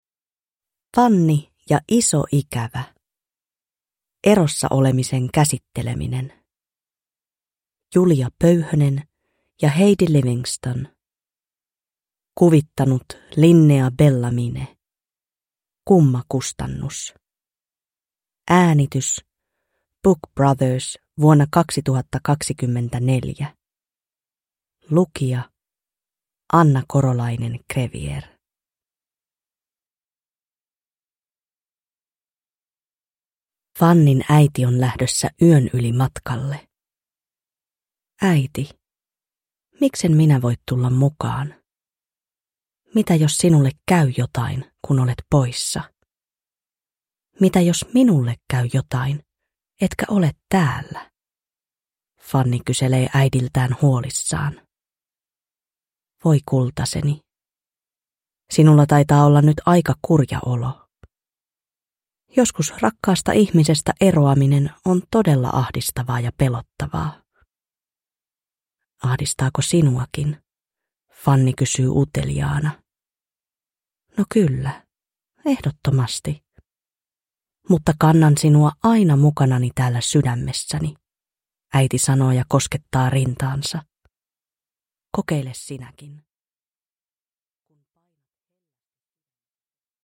Fanni ja iso ikävä – Ljudbok